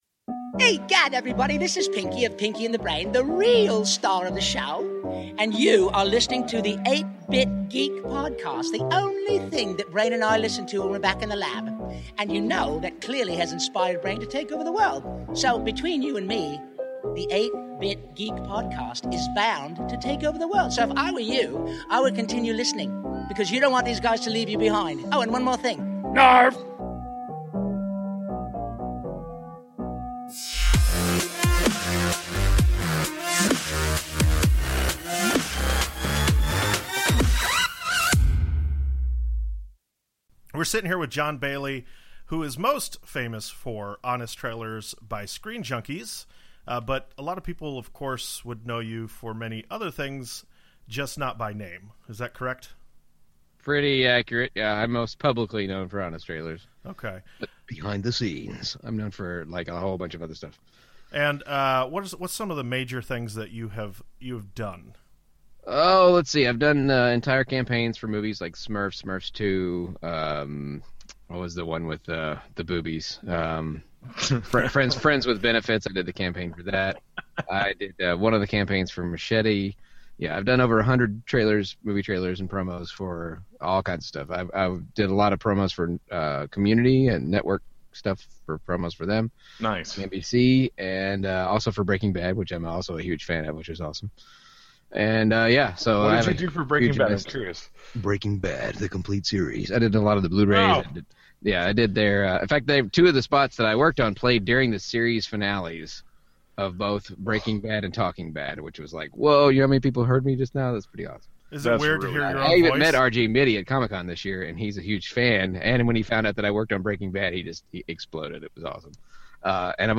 Jon Bailey (The Voice Behind Honest Trailers) Interview